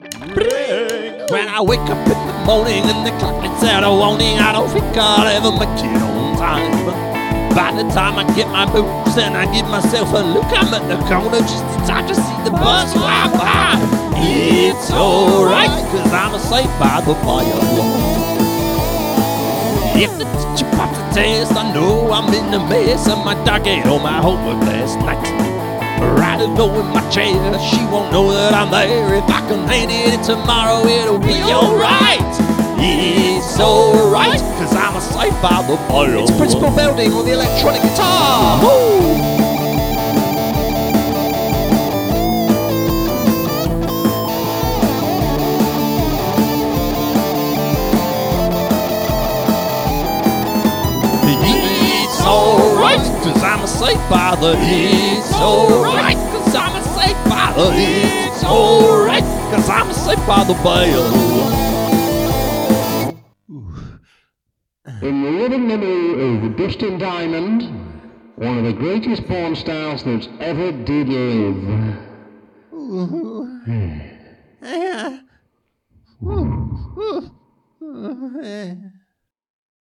Guitar solo by principal Belding